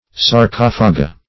Sarcophaga \Sar*coph"a*ga\, n. pl. [NL., neut. pl. See
sarcophaga.mp3